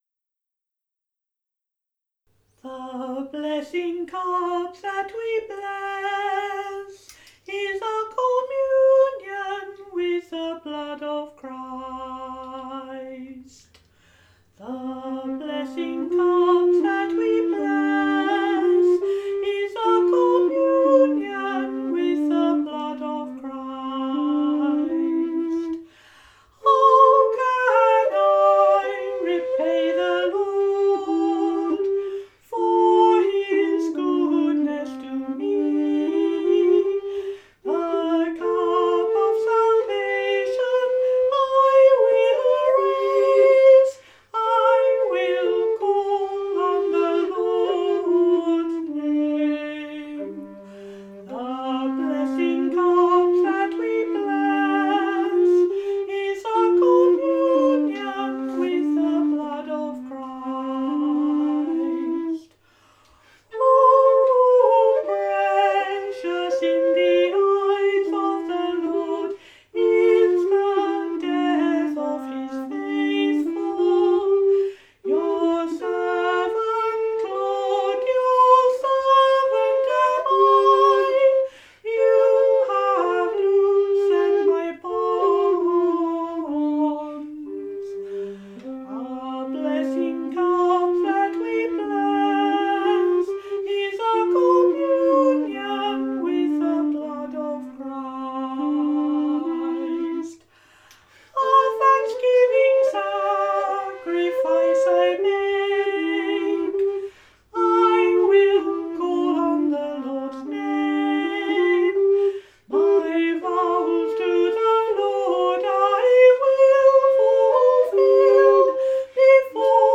playing the recorder